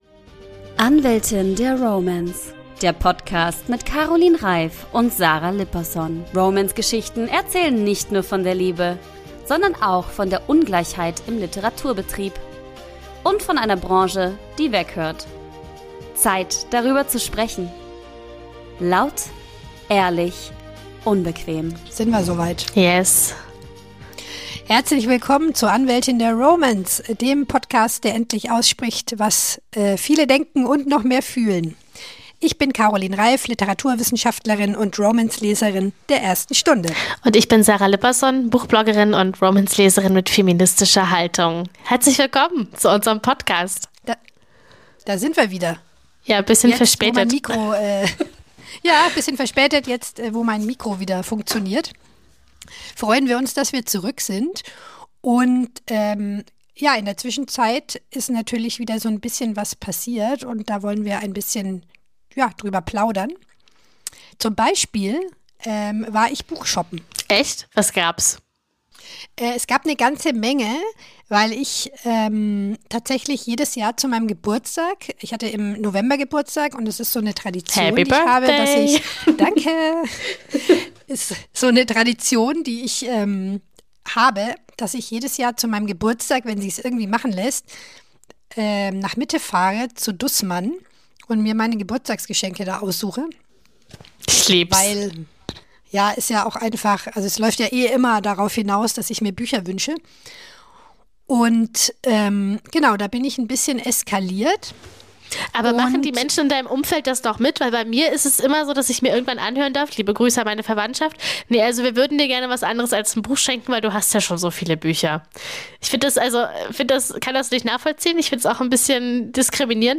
Beschreibung vor 4 Monaten Ist Romance schon Mainstream? Die beiden Podcaterinnen wissen es nicht so genau, diskutieren sich aber kurz um Kopf und kragen.